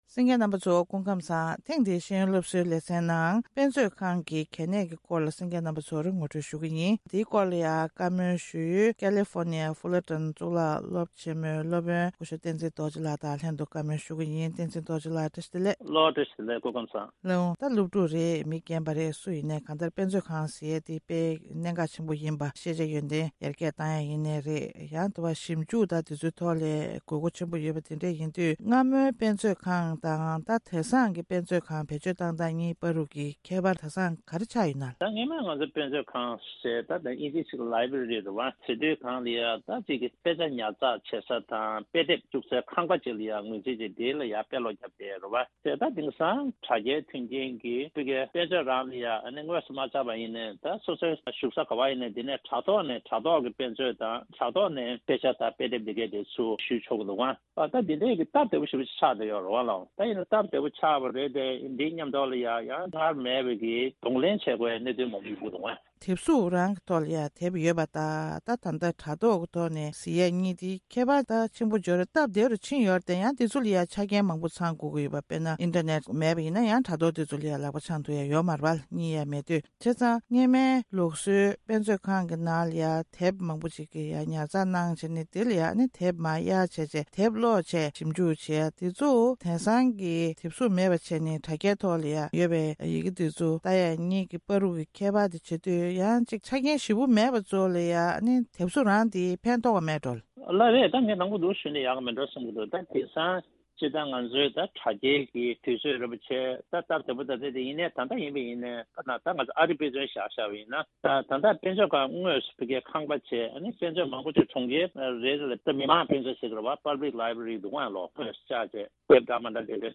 གནས་འདྲི་ཞུས་པ་ཞིག་གསན་གྱི་རེད།